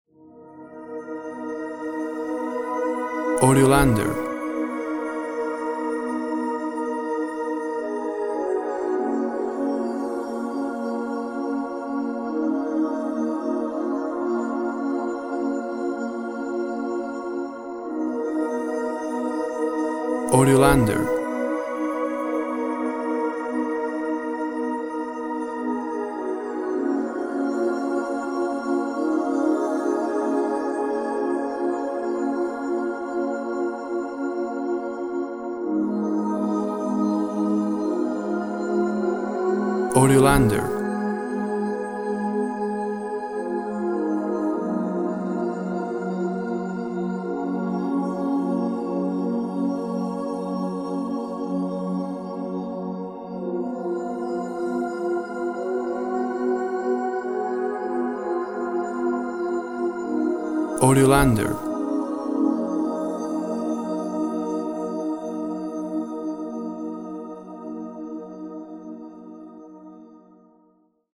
Dreamy wistful synth sounds.
Tempo (BPM) 54